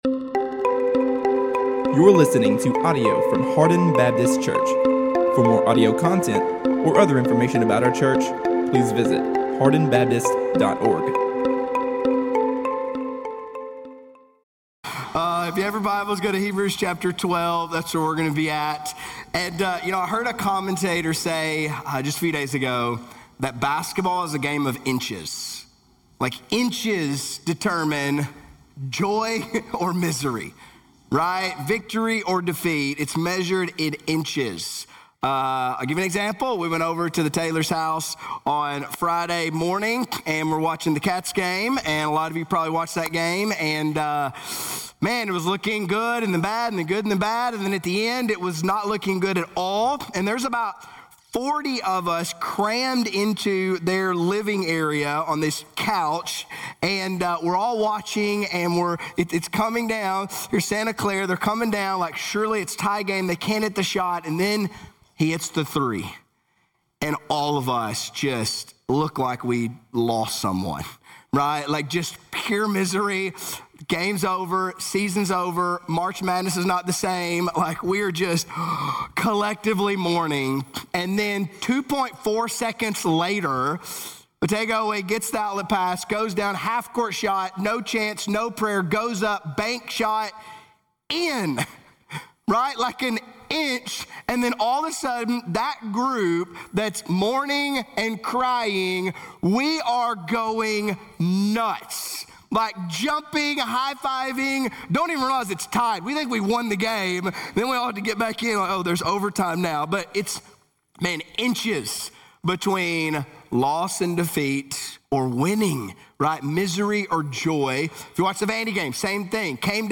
All Sermons – Hardin Baptist Church